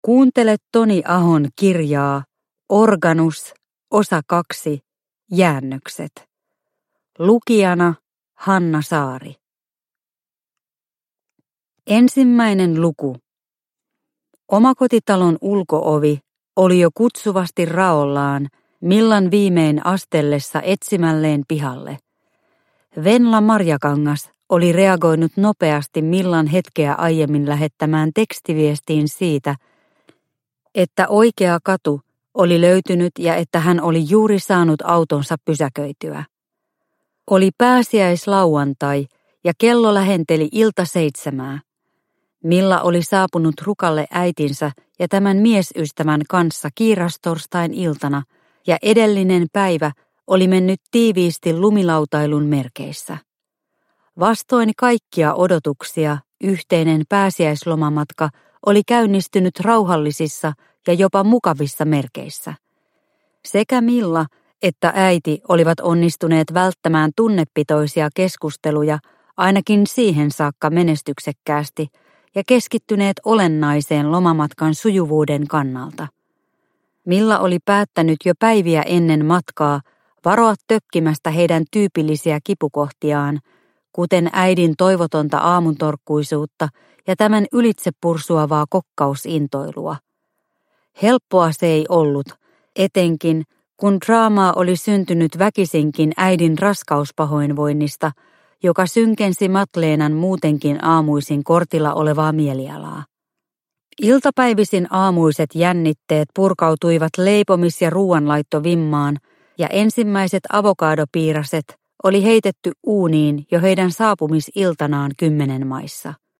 Organus – Ljudbok – Laddas ner